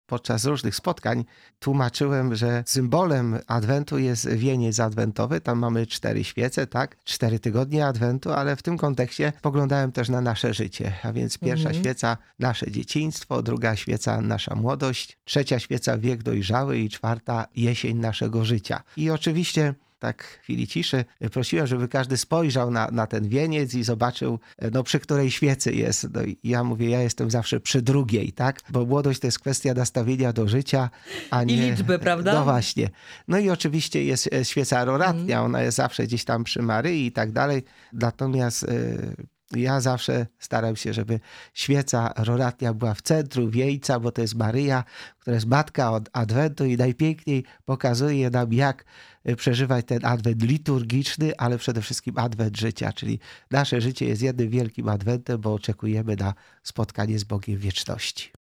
Jak przeżyć ten czas i doświadczyć Bożej Miłości? – pytamy ojca biskupa Jacka Kicińskiego.
-Adwentem nasze życie jest, mówi ojciec bp Jacek Kiciński.